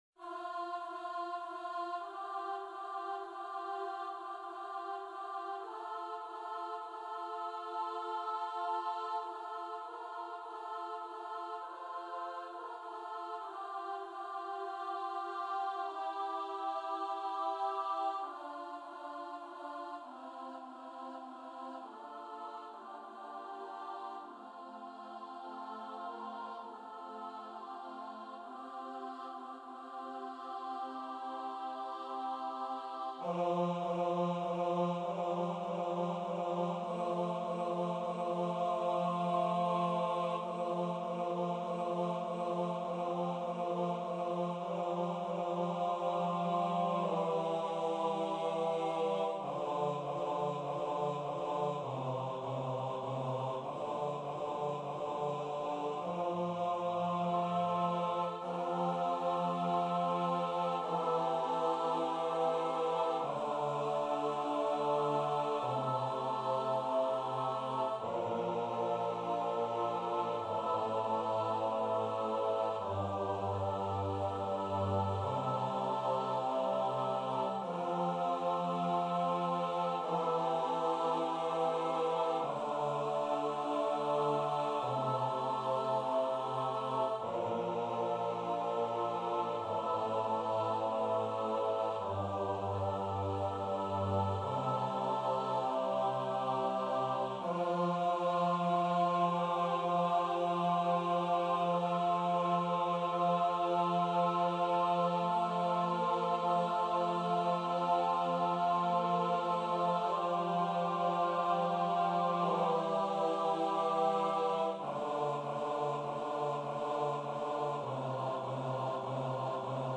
- Œuvre pour choeur à 8 voix mixtes (SSAATTBB) a capella
MP3 rendu voix synth.
Bass 1